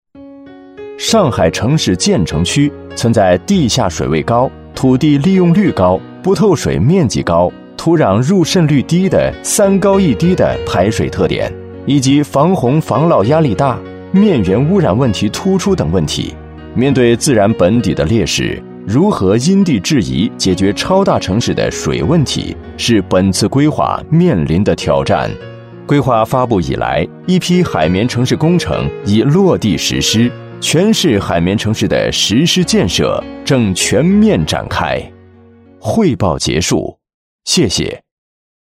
• 15专业男声4
专题汇报-清朗悦耳